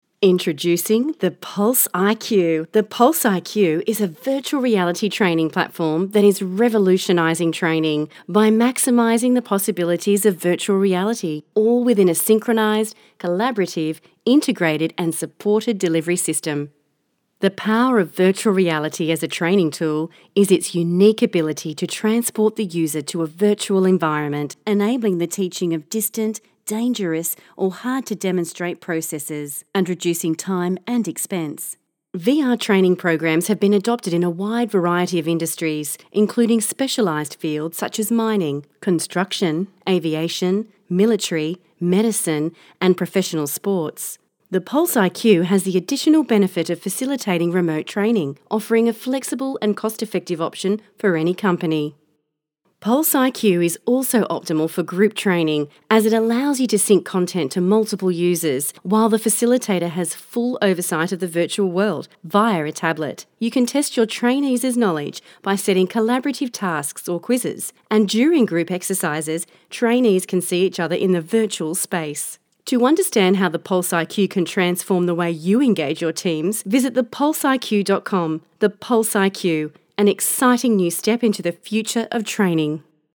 Female
English (Australian)
Yng Adult (18-29), Adult (30-50)
My voice is natural, warm, conversational, youthful, sassy, mature and authoritative and great for retail 'hard sell'.
All our voice actors have professional broadcast quality recording studios.